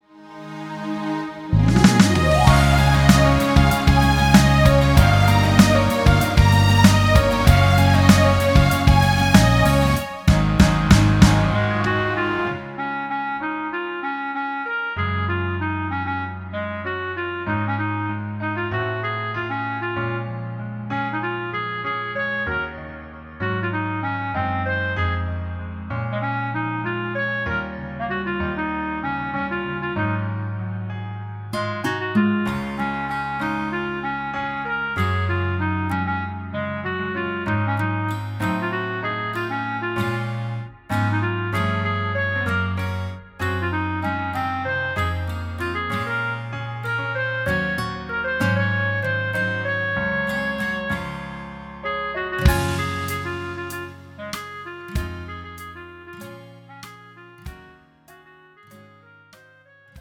음정 -1키 4:22
장르 가요 구분 Pro MR